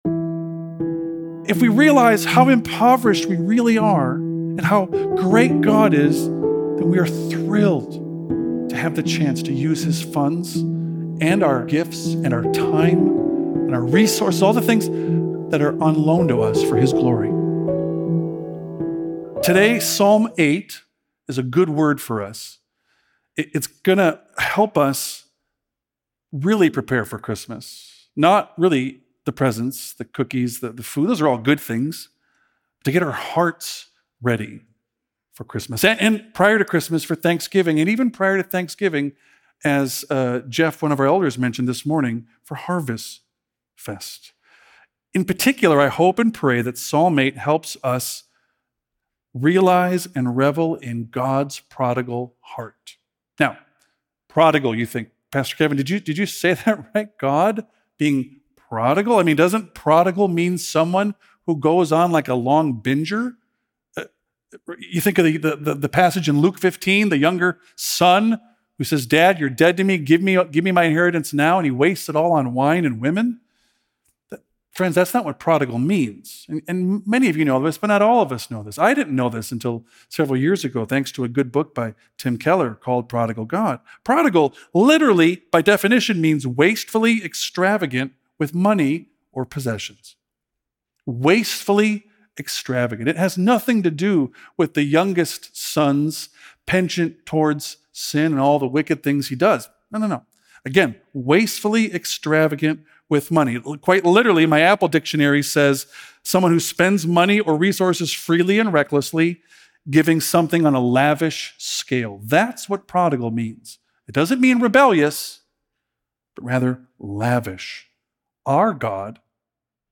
A message from the series "1 Peter." Christ-centered hope leads to holy living. Christians should set their hope fully on the grace of Jesus Christ, and that this hope should lead to holy living, which includes imitating God and having a holy fear of Him.